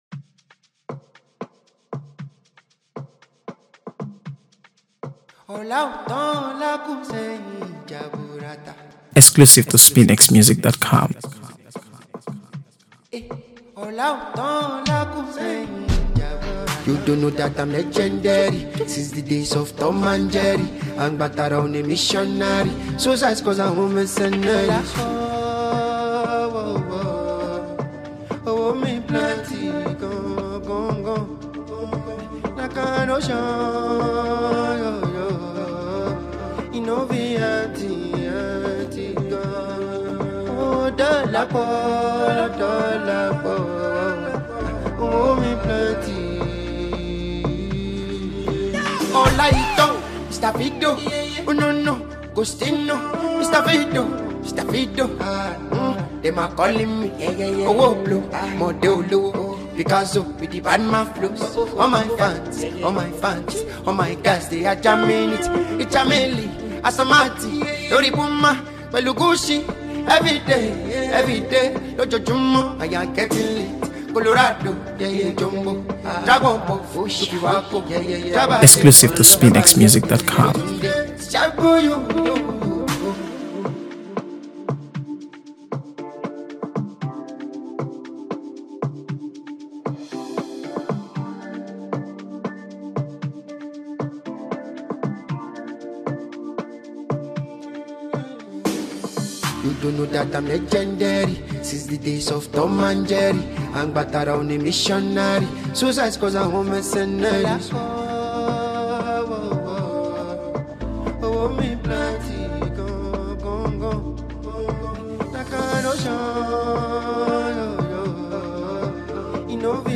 AfroBeats | AfroBeats songs
If you’re in the mood for something fresh and uplifting